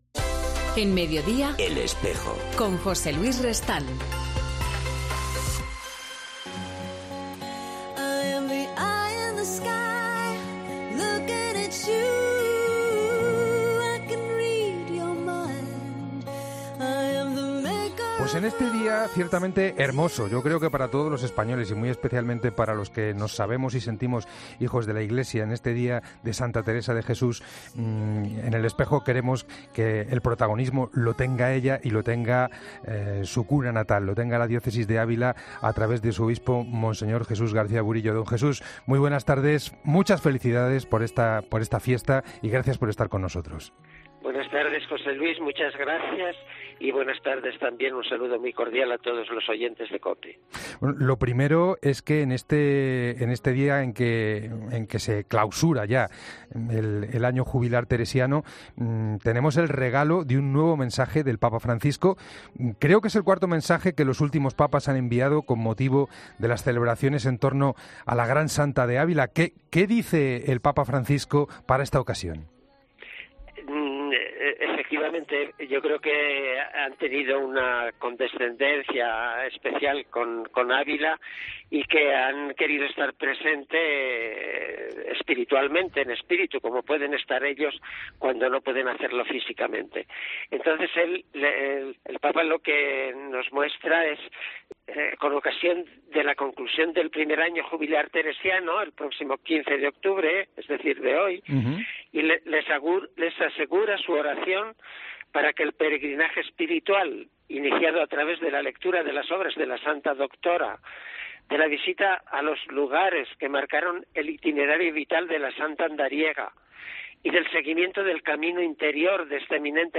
Mons. Jesús García Burillo habla del impacto en la Diócesis de Ávila del Año Jubilar Teresiano